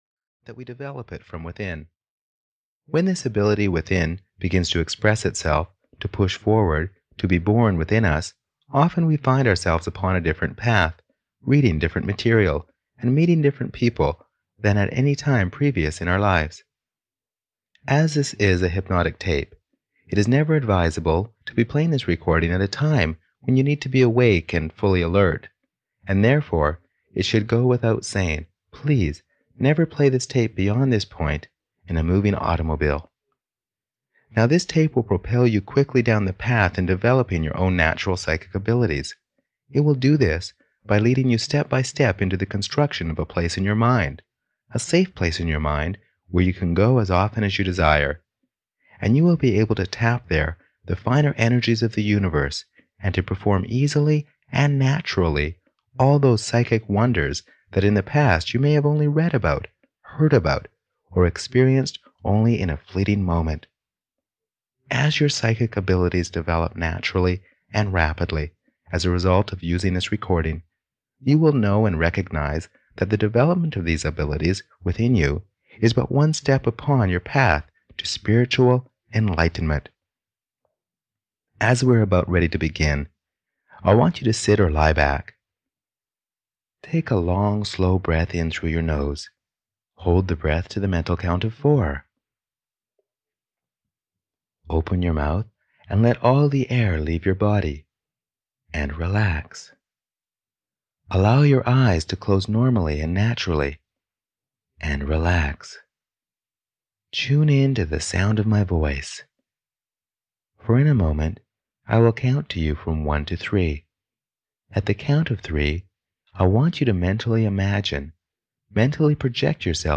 Be Psychic (EN) audiokniha
Find out just how much psychic power you actually possess. The "Be Psychic" self-hypnosis program is "laser targeted" to develop and use your hidden psychic skills.